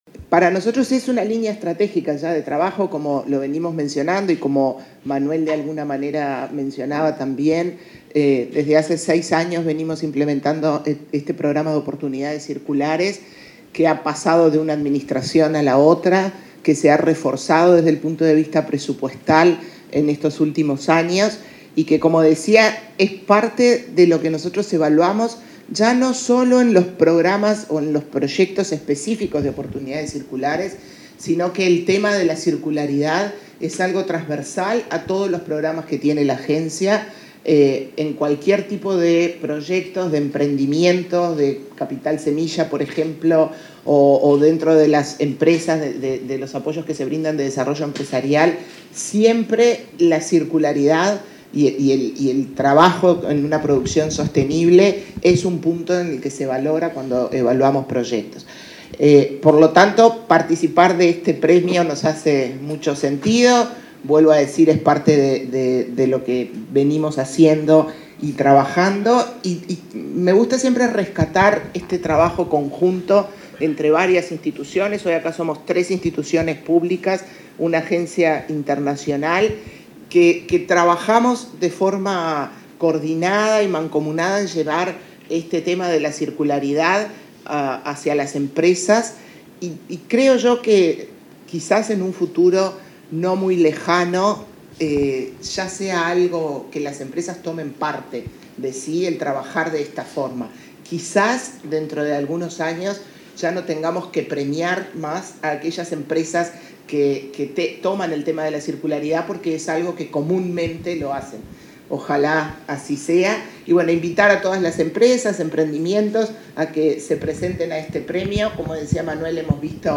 Palabras de autoridades en lanzamiento de Premio Uruguay Circular
Palabras de autoridades en lanzamiento de Premio Uruguay Circular 08/08/2023 Compartir Facebook X Copiar enlace WhatsApp LinkedIn Este martes 8 en Montevideo, la presidenta de la Agencia Nacional de Desarrollo (ANDE), Carmen Sánchez; el titular de la Agencia Nacional de Investigación e Innovación (ANII), Flavio Caiafa, y el subsecretario de Industria, Walter Verri, participaron en el lanzamiento de la quinta edición del Premio Uruguay Circular.